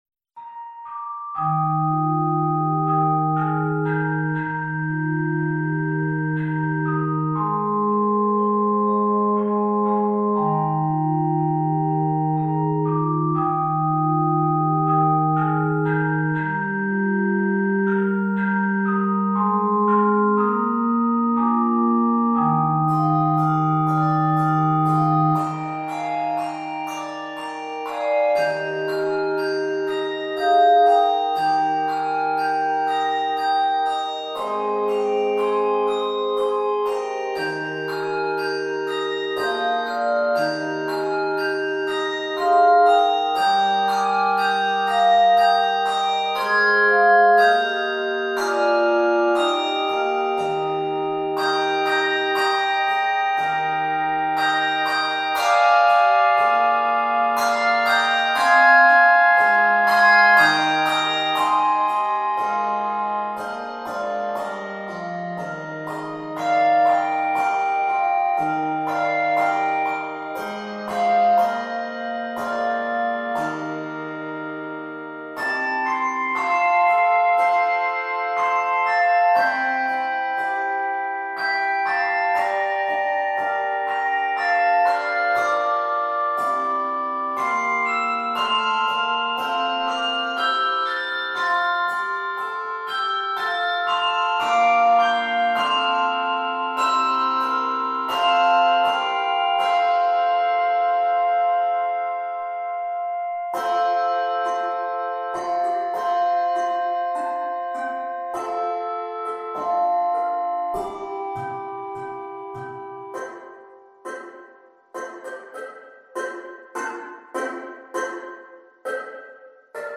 Keys of Eb Major and F Major.